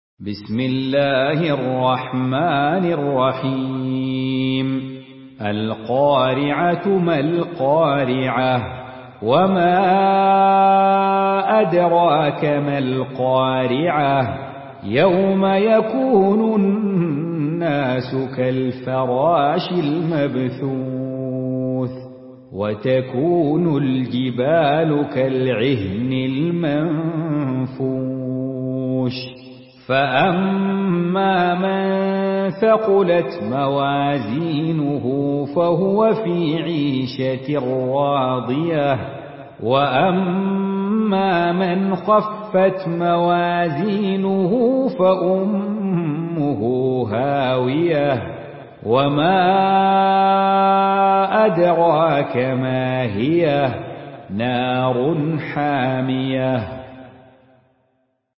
مرتل